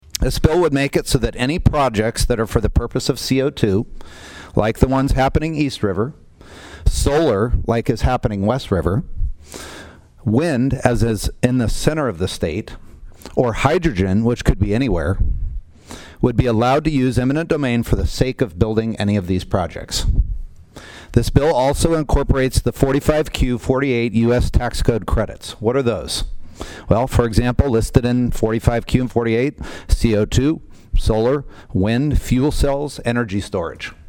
PIERRE, S.D.(HubCityRadio)- The South Dakota Senate State Affairs Committee heard testimony on SB49.
Carley explains the purpose of the bill.